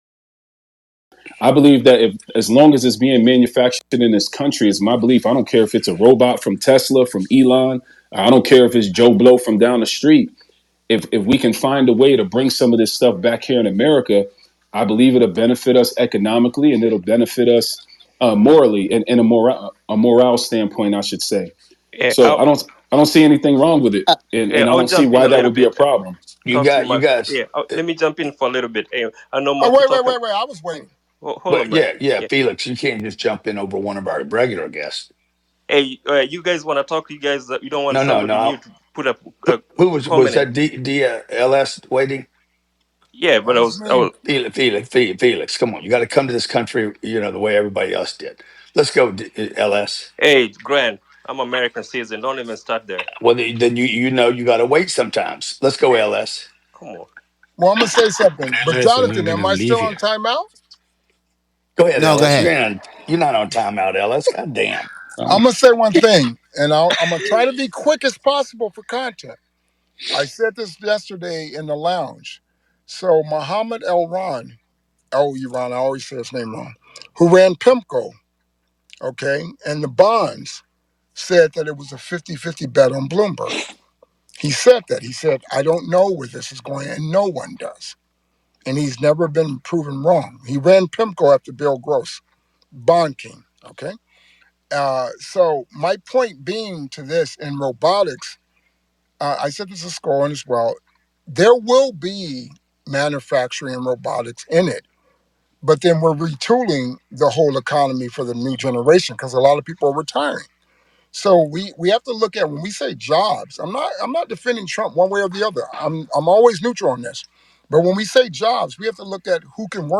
The instant feedback – the laughs, the gasps, that sense of connection.
We Allow You To Present Your Interpretation On Today's Local, National, & World News Topics. Spirited & Informed Discussions Are Encouraged.